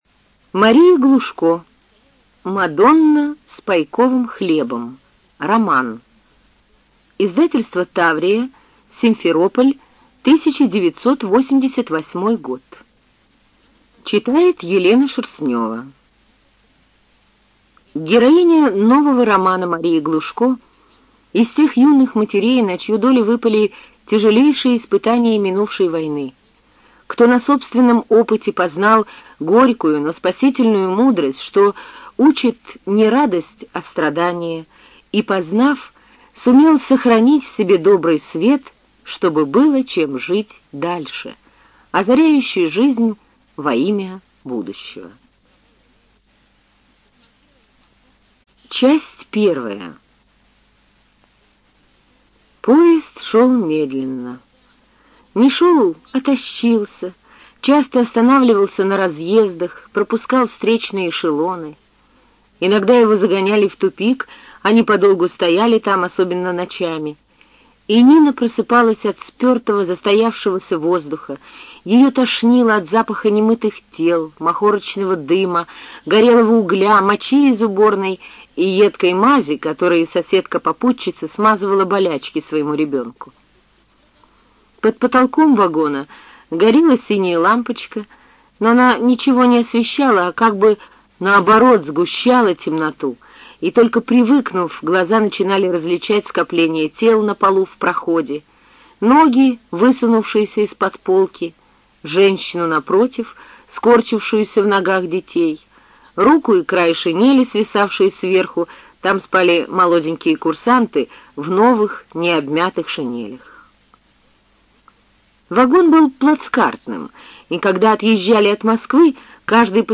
ЖанрСовременная проза
Студия звукозаписиРеспубликанский дом звукозаписи и печати УТОС